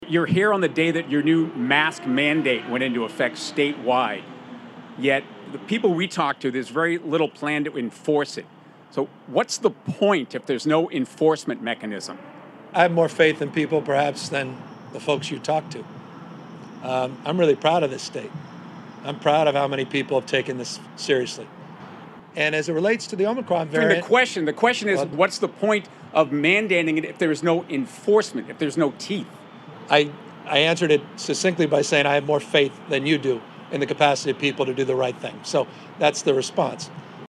The current statewide mask mandate began on December 15th, 2021. However, Governor Gavin Newsom told a reporter that there is no actual enforcement for it.